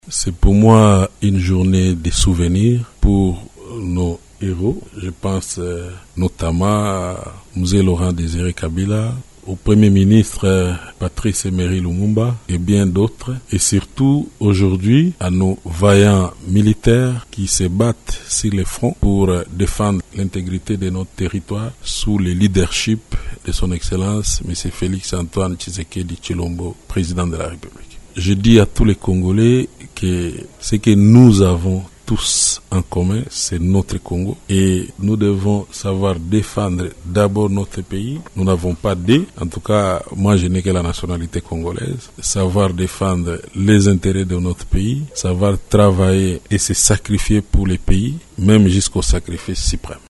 Il a lancé ce message lors de la commémoration, ce jeudi 16 janvier, de l’assassinat de Laurent-Désiré Kabila.